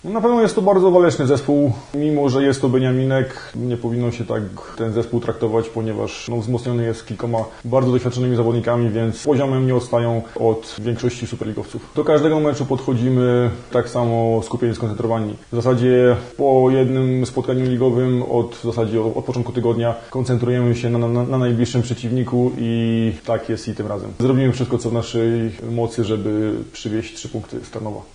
zawodnik mielczan.